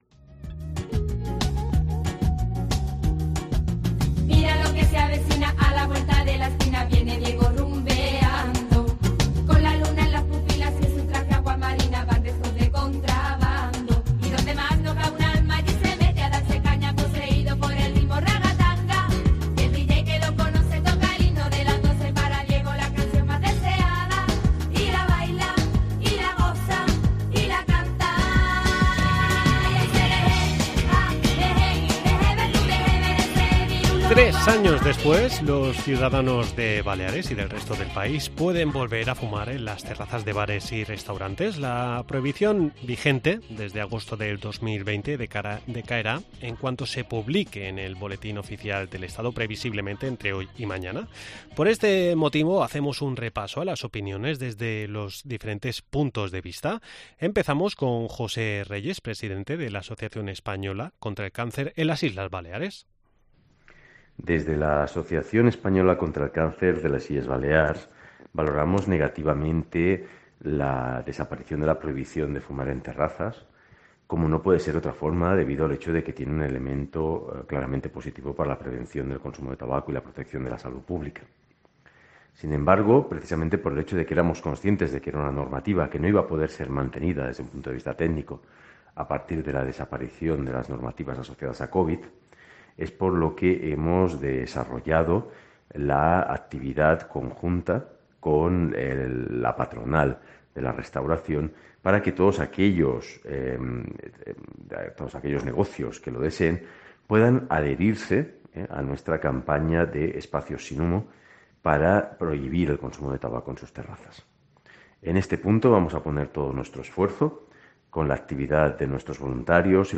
Salimos a la calle para conocer la opinión del ciudadano respecto a la derogación de la prohibición de fumar